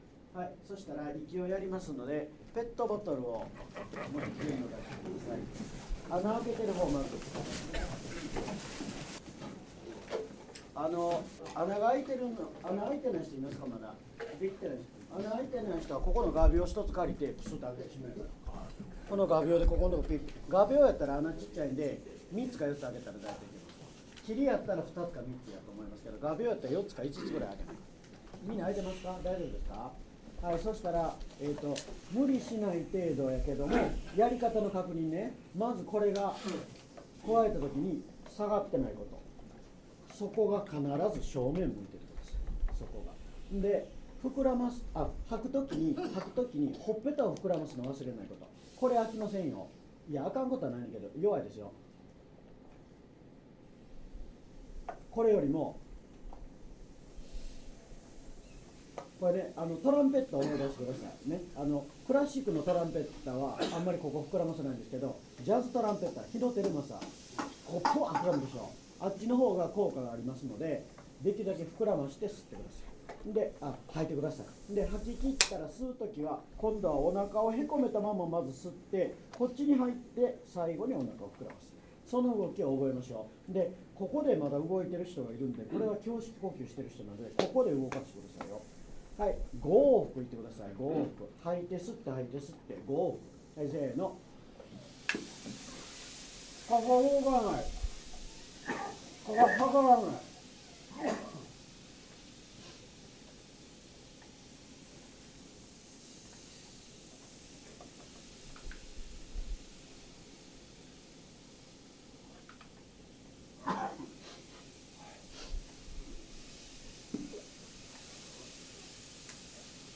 これを使っての先生の指導　　　　　　　　（2012年10月）